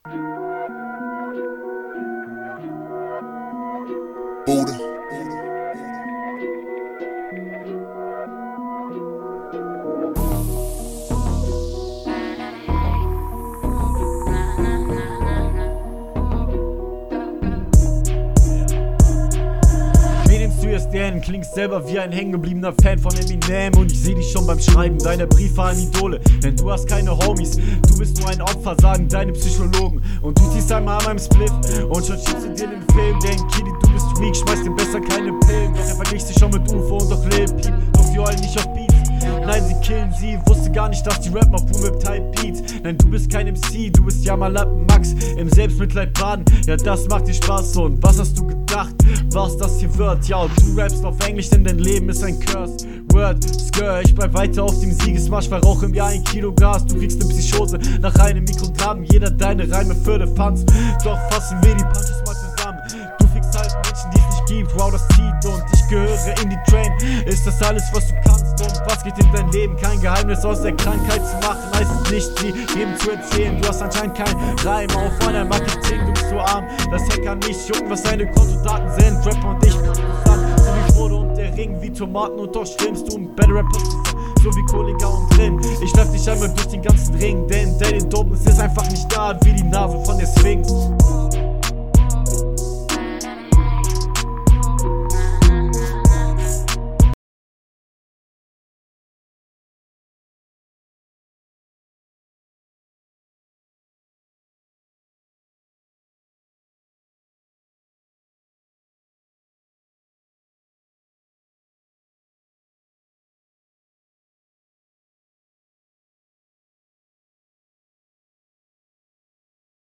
Zum teil kann man in du dieser runde nichts verstehen, da du immer leiser wirst.
hast wieder das problem, dass du zwischendurch zu weit vom mikro weg bist. wenn die …